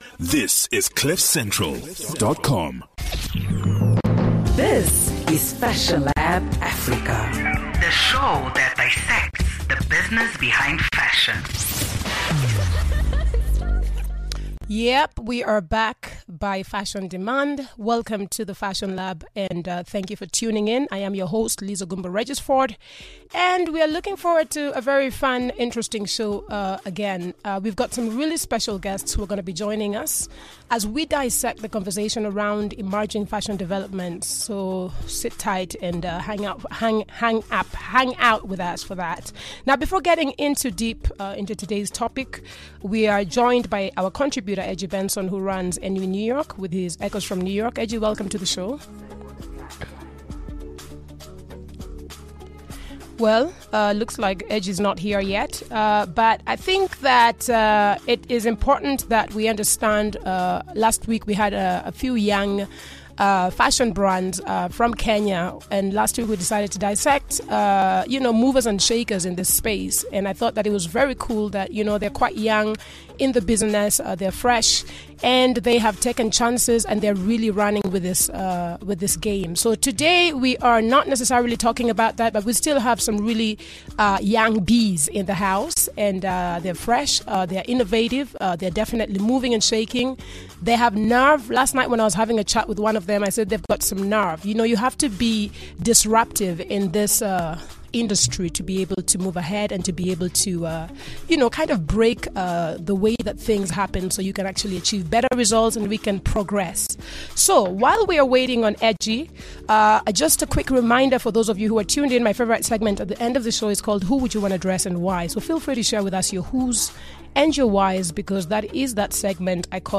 Fashion Lab is the first Fashion-Business radio show to go live on air in Africa.